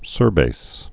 (sûrbās)